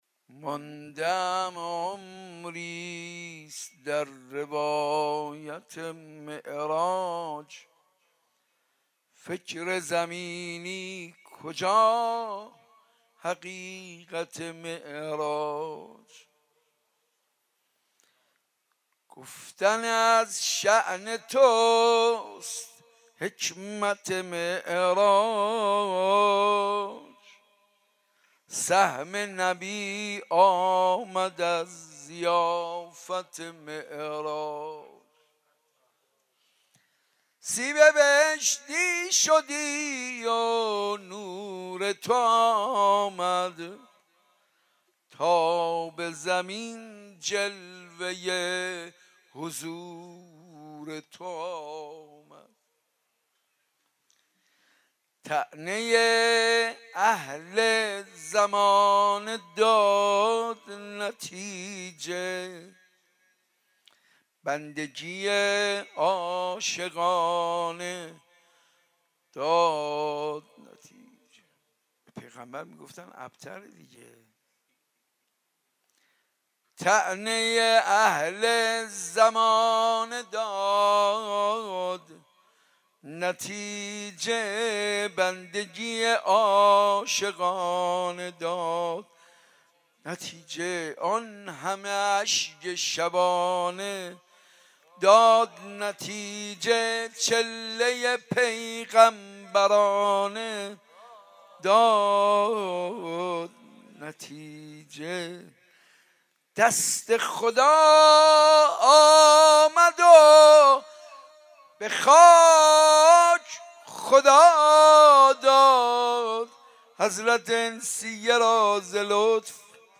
مراسم ولادت حضرت زهراء
به مولودی خوانی و مدیحه سرایی پرداختند.